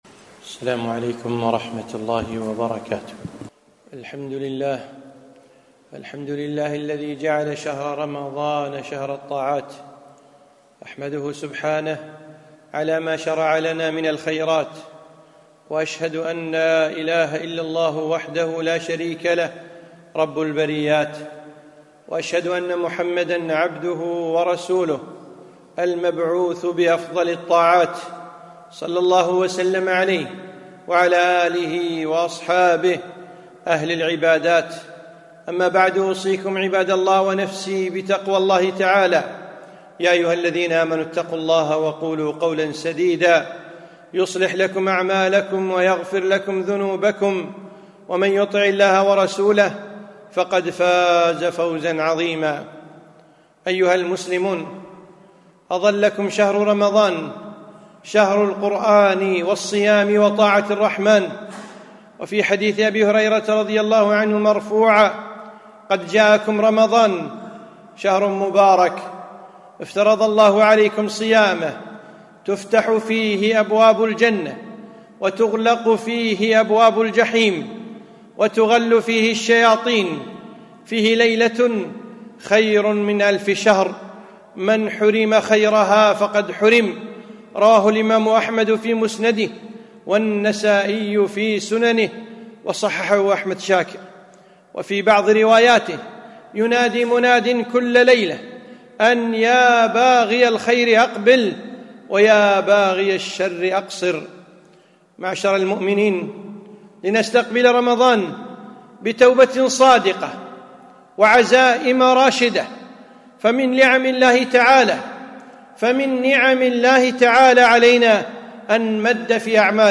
خطبة - شهر الطاعة